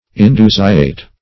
Search Result for " indusiate" : The Collaborative International Dictionary of English v.0.48: Indusiate \In*du"si*ate\, Indusiated \In*du"si*a`ted\, a. (Bot.)